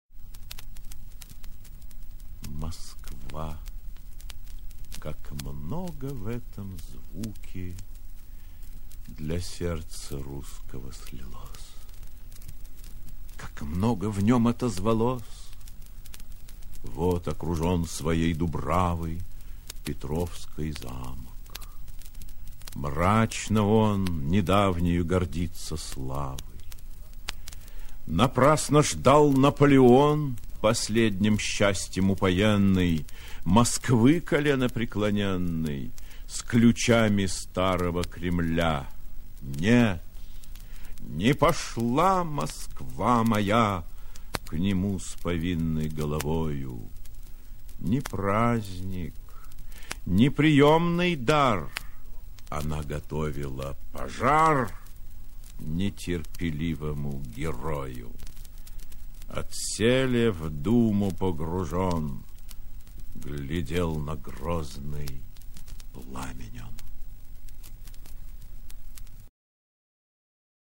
Прослушивание отрывка из «Евгения Онегина» о Москве в исполнении А. Консовского:
- Москва! – Восклицание мягкое, протяжное, с любовью и теплотой в голосе.
- Как много в этом звуке // Для сердца русского слилось! – Упор на слово много, задумчивость в голосе.
- Как много в нем отозвалось! – Ускорение темпа, резкость в голосе от воспоминаний о войне 1812 г. Слово отозвалось с повышающейся интонацией.
- Мрачно он // Недавнею гордится славой. – Упор на слове мрачно с устрашающей мрачностью в голосе.
- Напрасно ждал Наполеон // Последним счастьем упоенный – Произносится бодро, ритмично, по-военному.
- Отселе, в думы погружен, // Глядел на грозный пламень он. – Слово пламень произнесено быстро и почти шепотом.